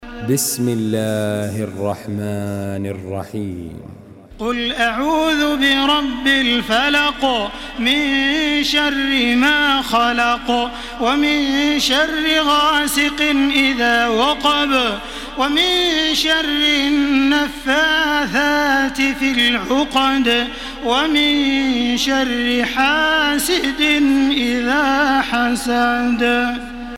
Surah Al-Falaq MP3 in the Voice of Makkah Taraweeh 1433 in Hafs Narration
Murattal